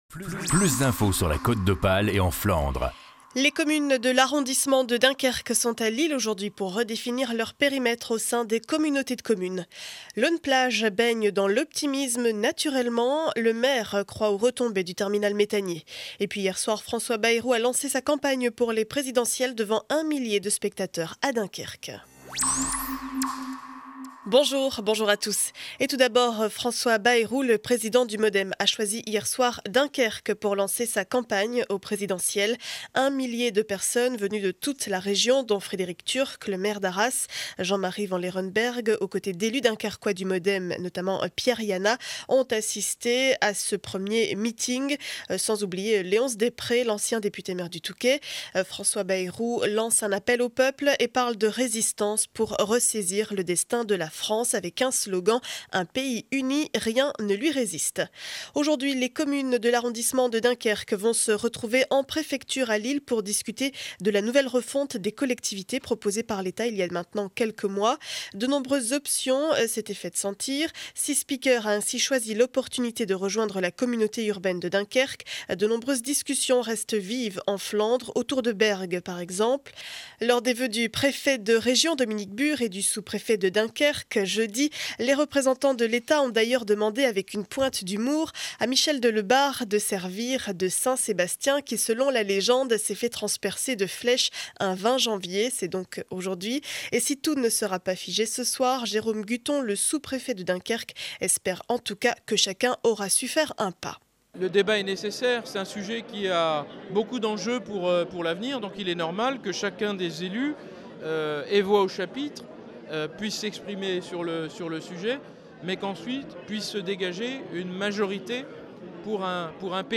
Journal du vendredi 20 janvier 2012 7 heures 30 édition du Dunkerquois.